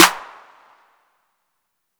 Tm8_Clap13.wav